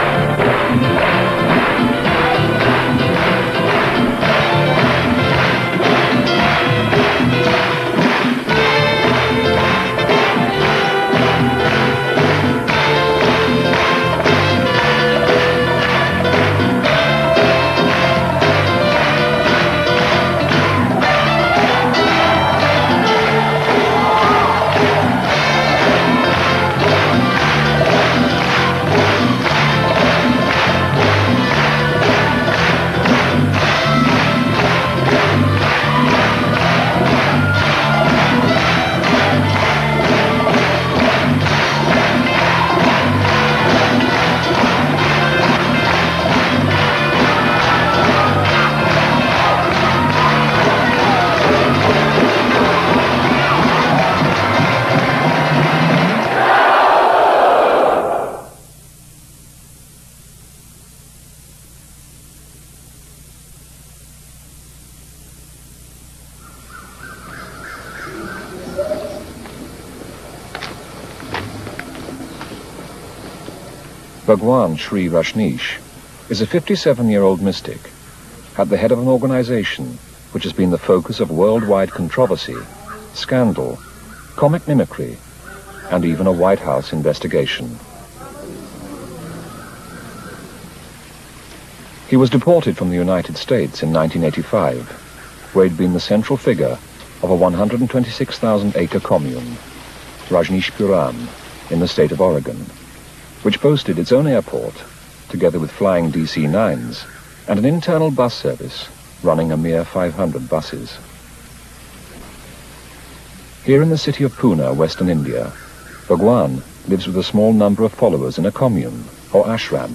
A TV documentary was made by ′Channel Four′ in 1988 presenting a look at Osho′s pull on his disciples. It′s a 25 min. video, rm-file for real player , (42 MB), called: Bhagwan - My Dance is complete .